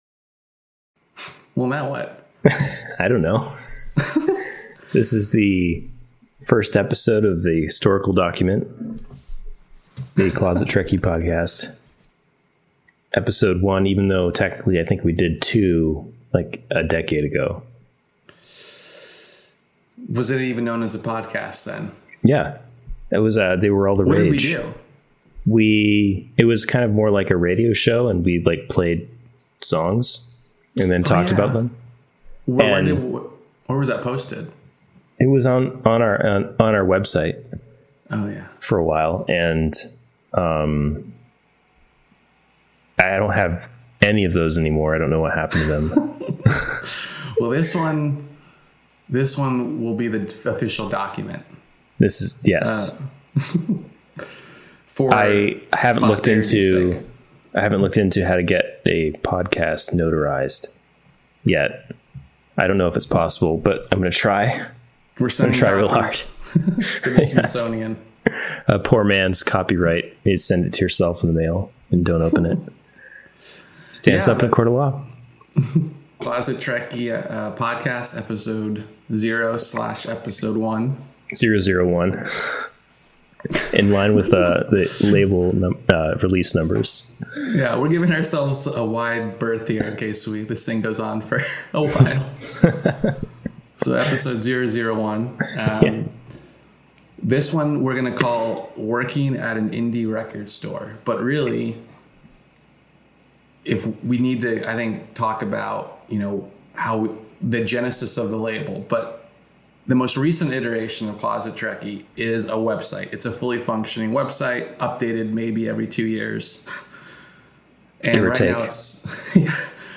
Two former record store employees reminisce.